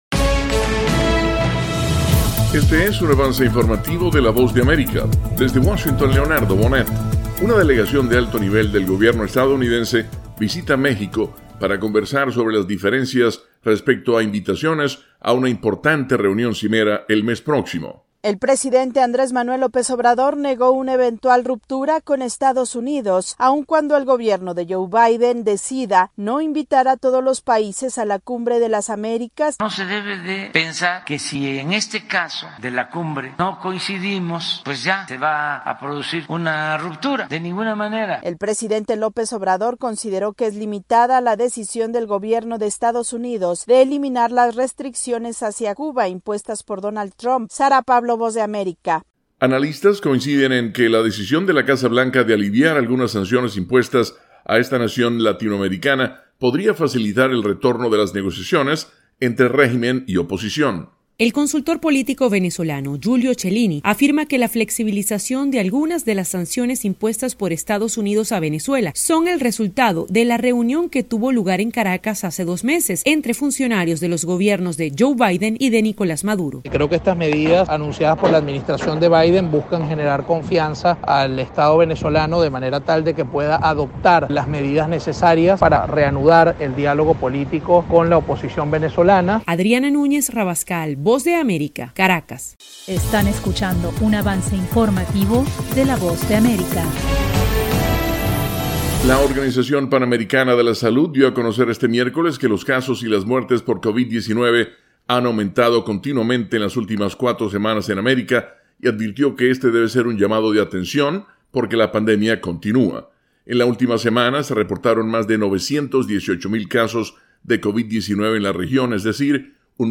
Avance Informativo - 3:00 PM